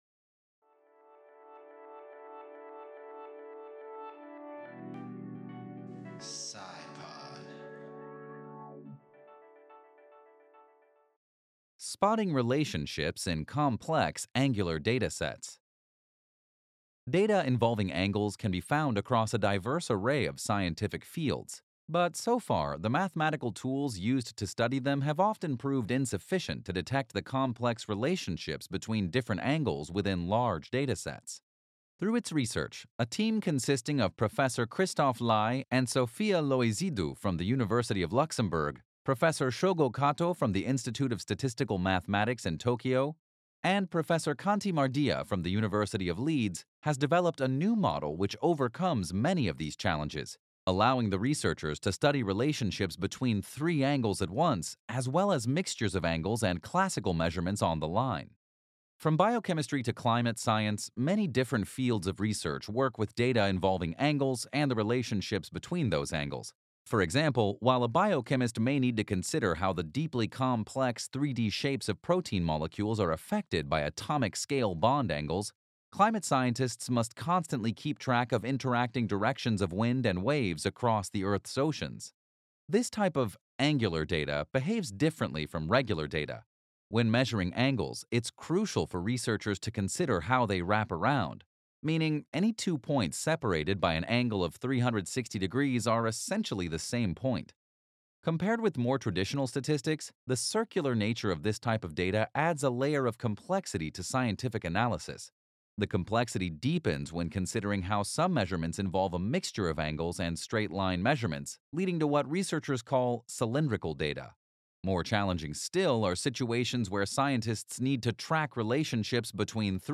Audiobook